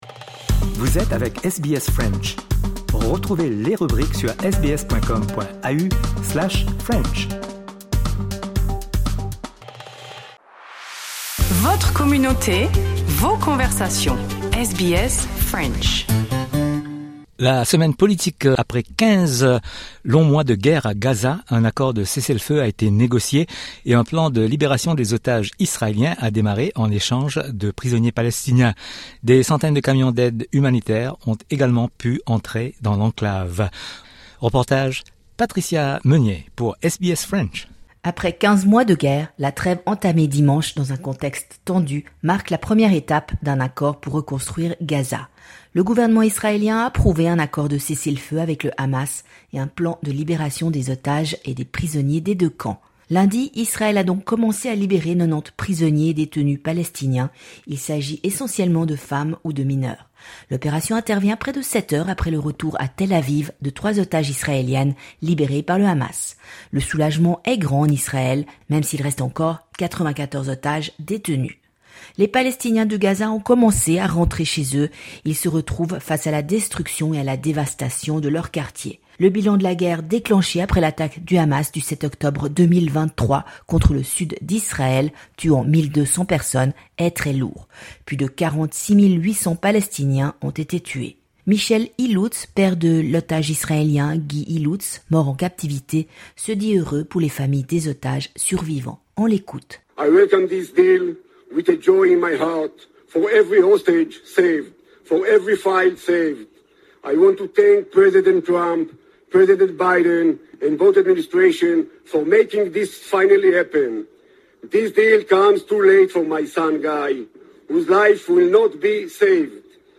Accord de cessez-le-feu à Gaza et la réaction de l'Australie. Reportage